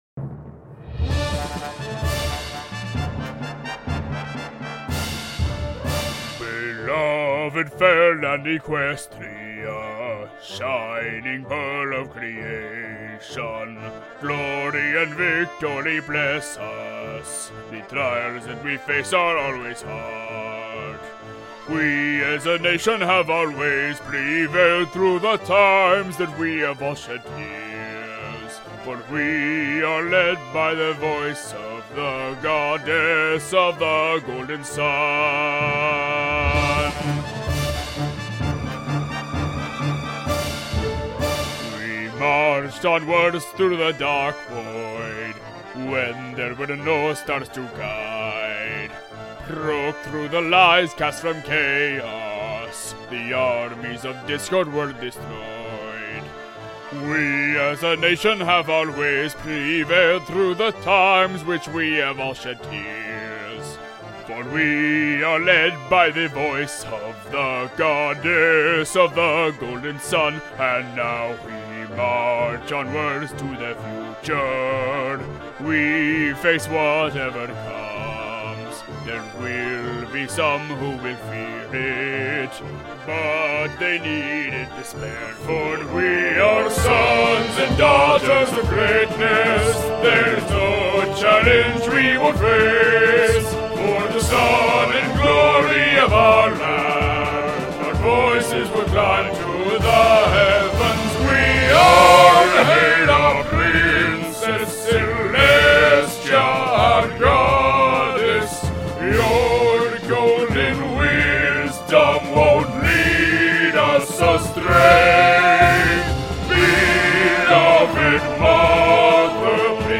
Equestrian anthem